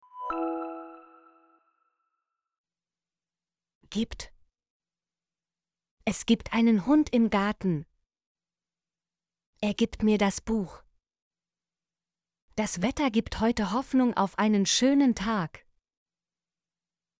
gibt - Example Sentences & Pronunciation, German Frequency List